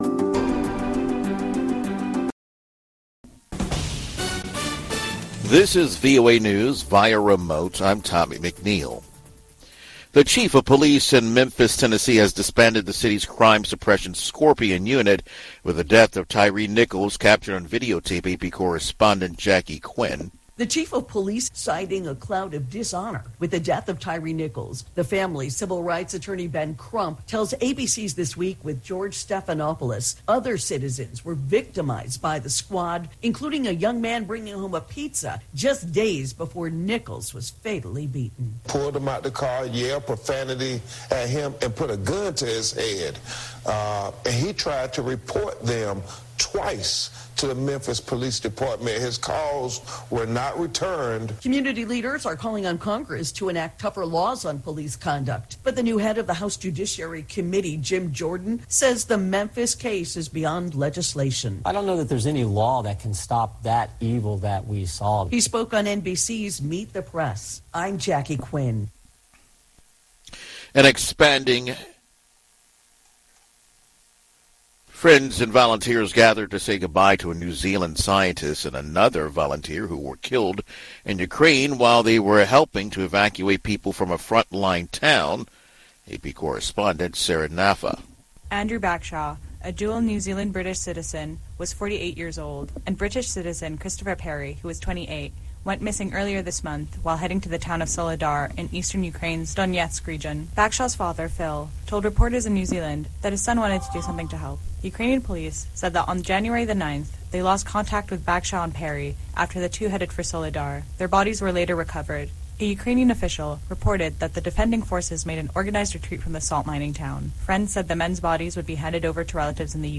Two-Minute Newscast